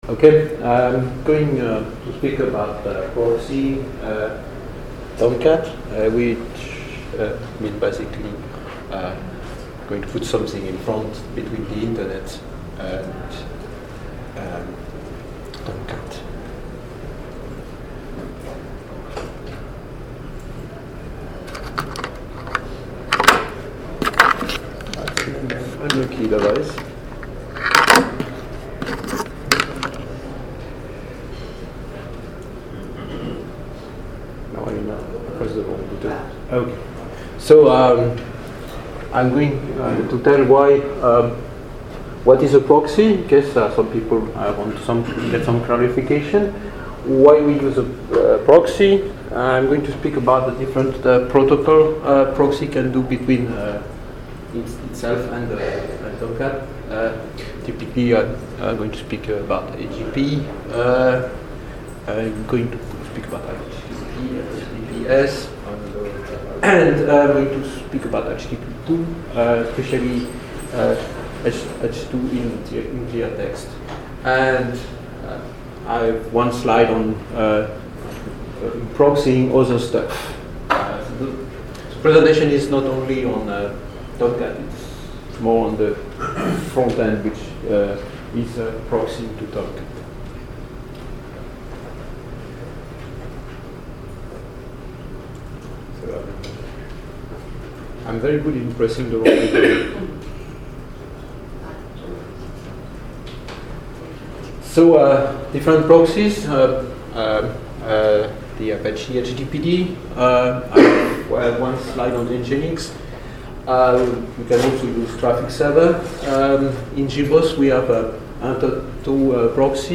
Categories: Uncategorized • Tags: ApacheCon, apacheconNA2017, Podcasts • Permalink